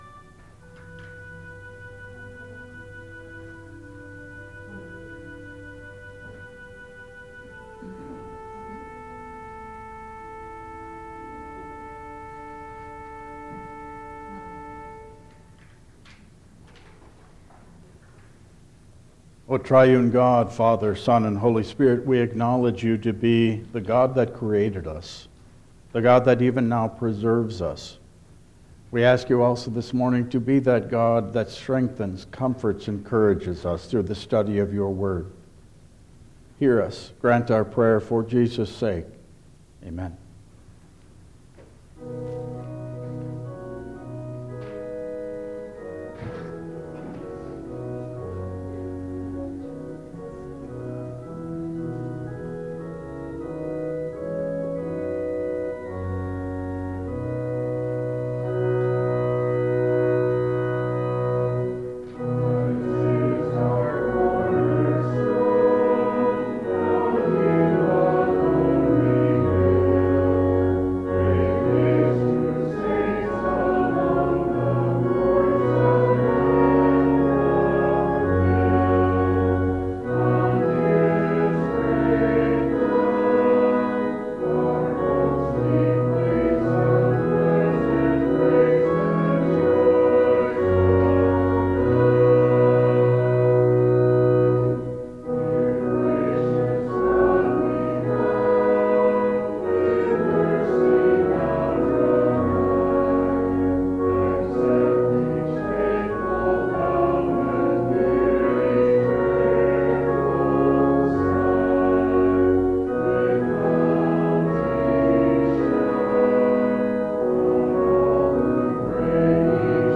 Passage: John 4:5-26,28-29, 39 Service Type: Regular Service